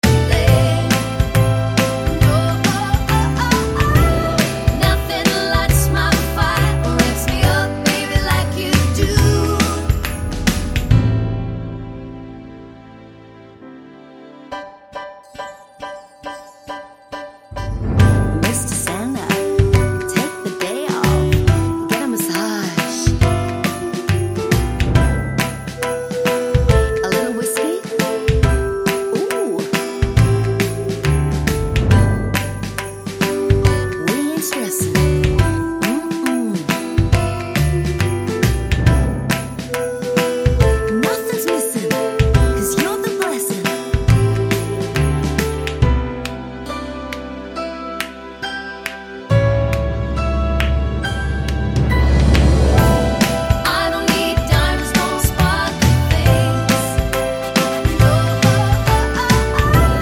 no Backing Vocals Christmas 3:03 Buy £1.50